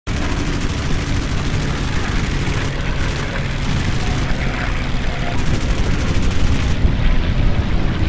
WW2, JAPAN: P 51 Mustangs Strafe sound effects free download
WW2, Japan: Footage shot from a USAAF P-51 gun camera shows a US pilot strafing two Japanese fisherman on a beach, the fisherman run for cover as rounds impact the sand around them sending sand spraying into the air.